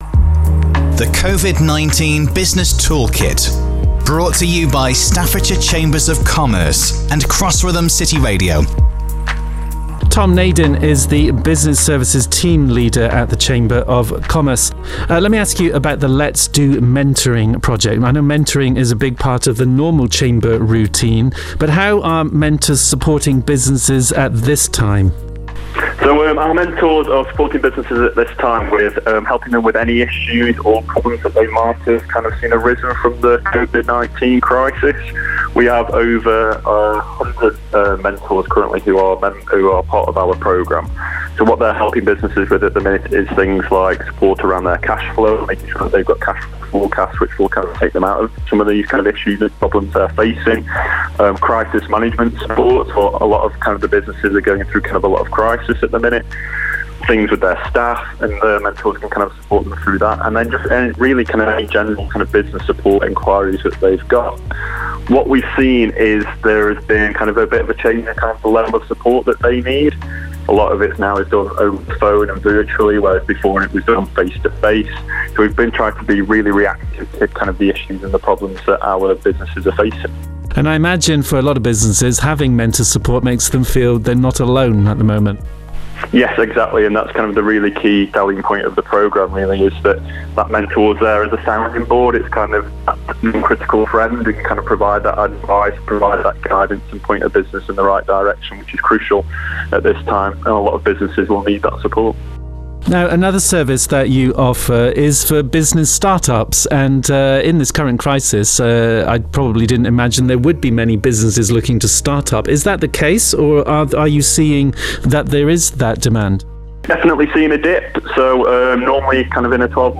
Each podcast features interviews with specialists from Staffordshire Chambers of Commerce and covers topics that businesses may find informative, such as: Funding advicejob retention & self employed schemesonline mentoringbusiness crime support and more...